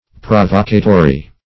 \Pro*vo"ca*to*ry\